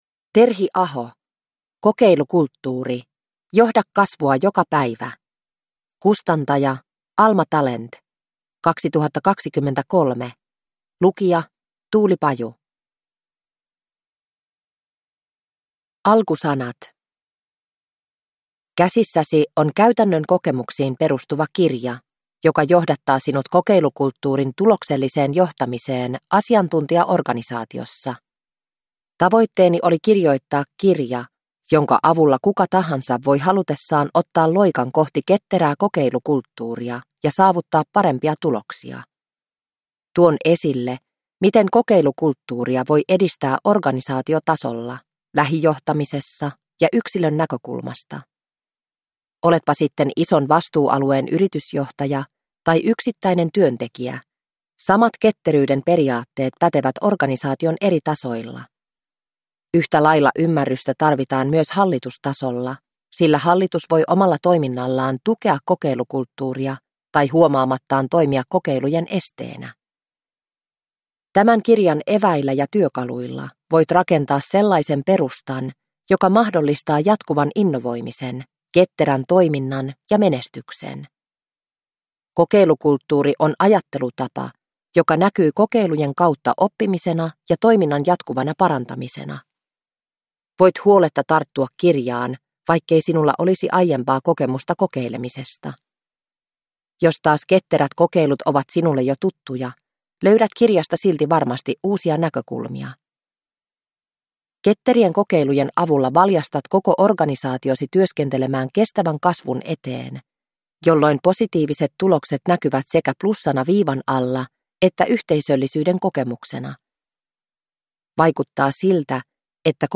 Kokeilukulttuuri – Ljudbok – Laddas ner